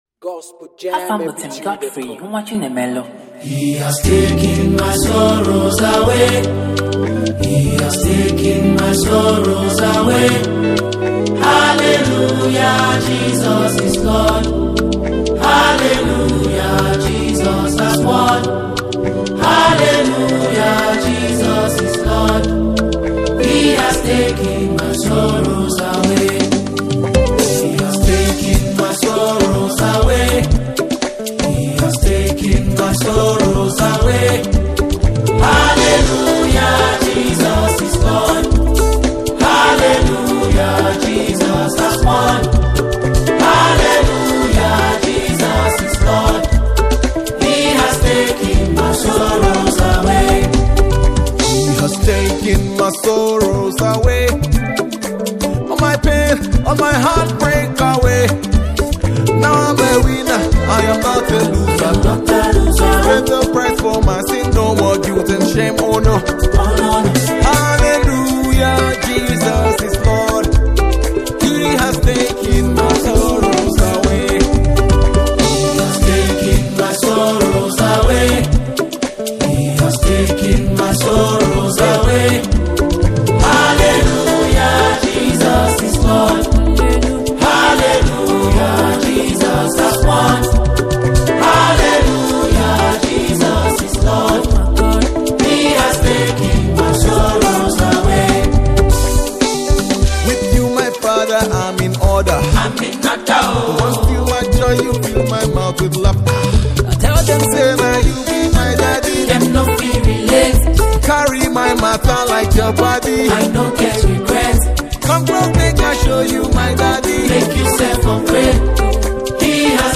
powerful and uplifting gospel sound